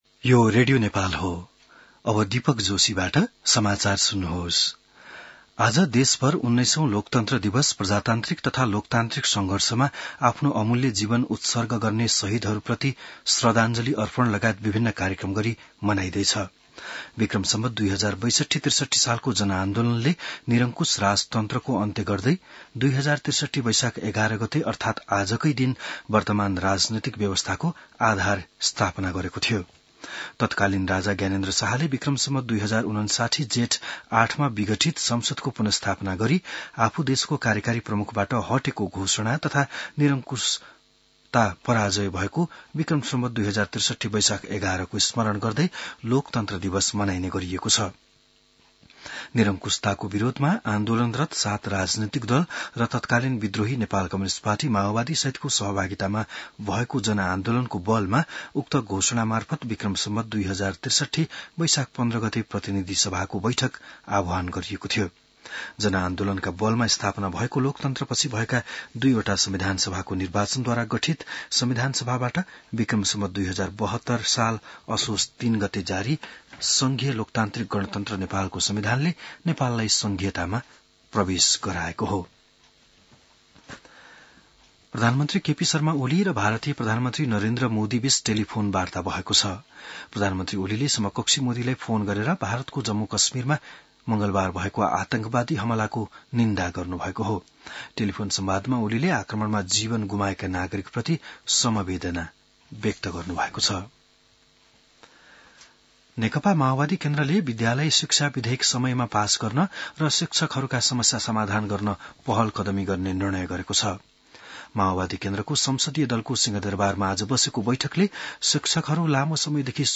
बिहान ११ बजेको नेपाली समाचार : ११ वैशाख , २०८२